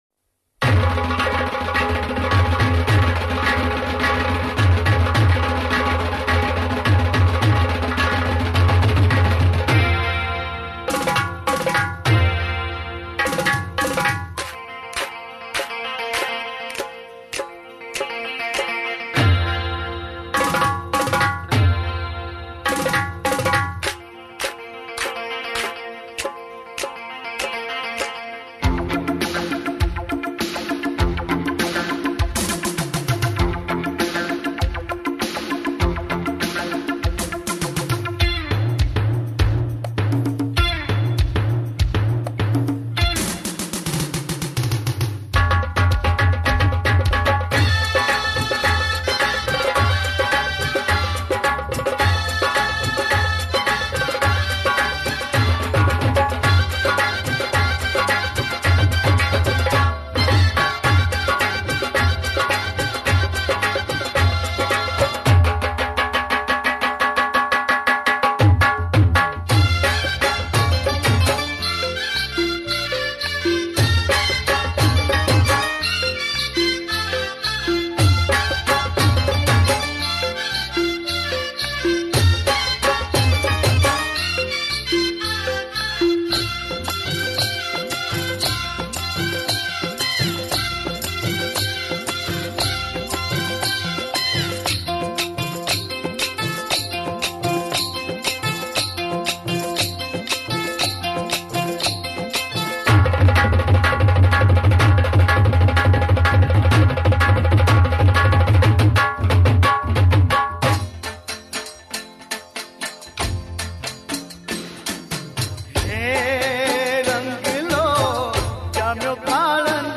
Instrumental
Navratri Garba Albums